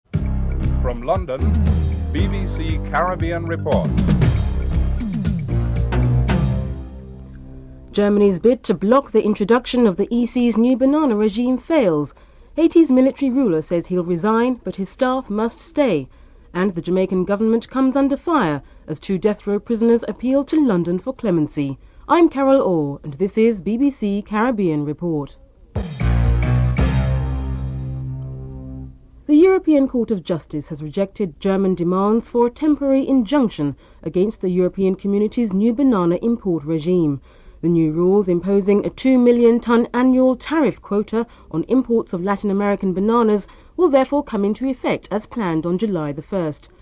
Headlines (00:00-00:36)
Interview